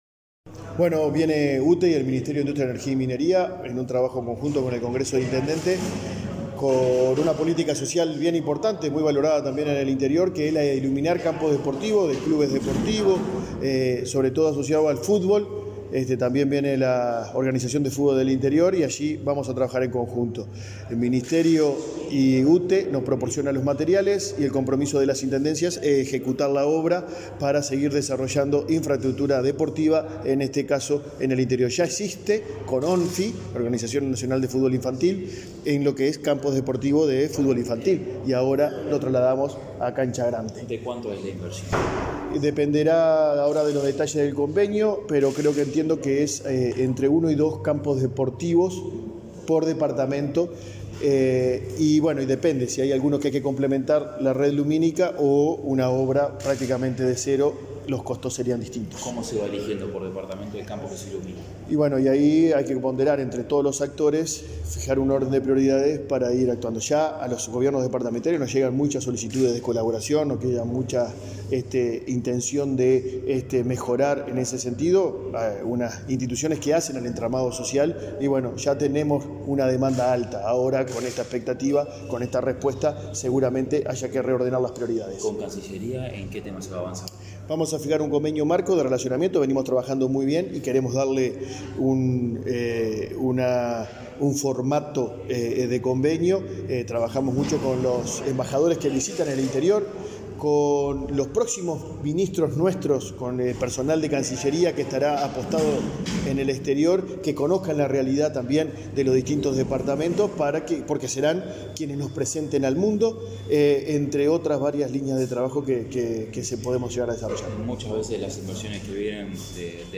Declaraciones del Congreso de Intendentes
Este jueves 17, el presidente del Congreso de Intendentes, Guillermo López, dialogó con la prensa sobre los convenios firmados con distintos